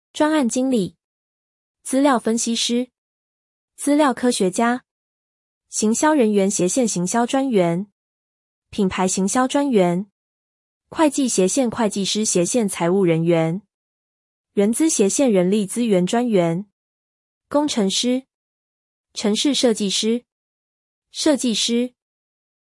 プロジェクトマネージャー 專案經理 zhuān àn jīng lǐ 「專案」は台湾で「プロジェクト」の意味。IT業界で頻出。